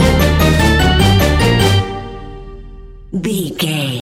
Aeolian/Minor
Fast
ominous
dark
dramatic
eerie
energetic
brass
synthesiser
drums
strings
horror music